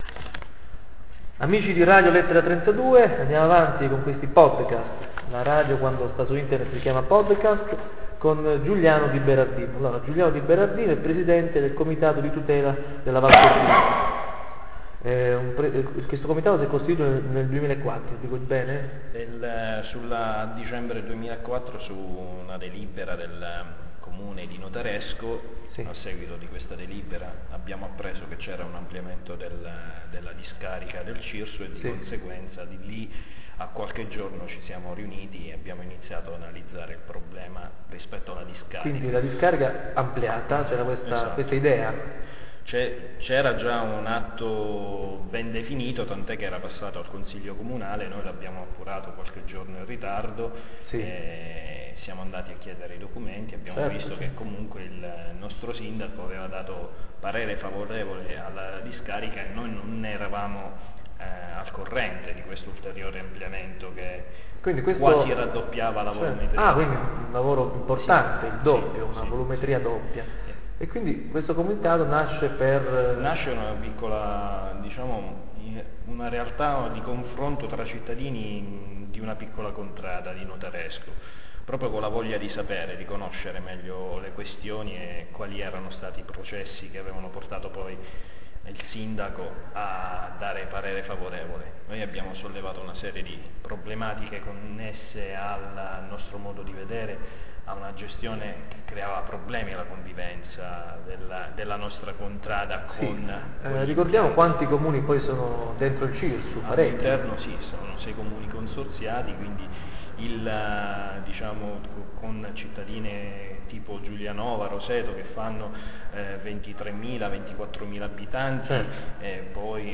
L'audio è un po' basso, alzate il volume.